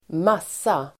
Uttal: [²m'as:a]